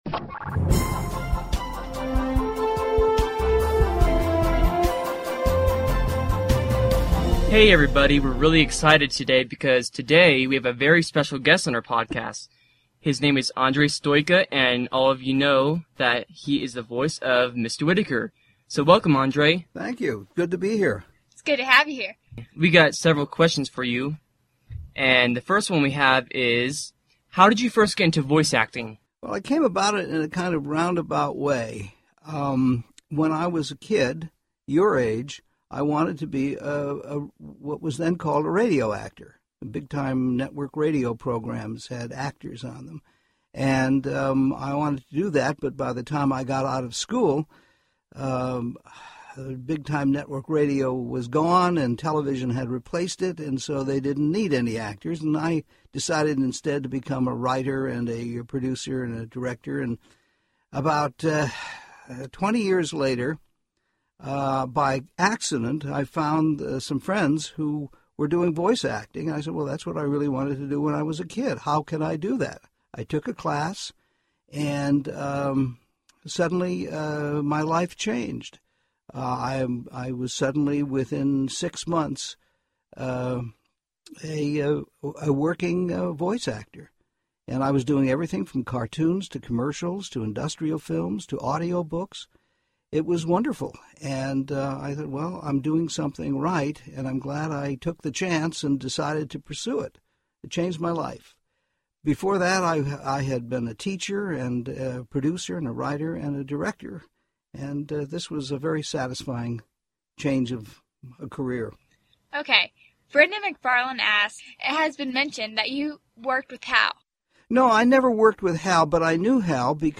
Excerpt from podcast #27.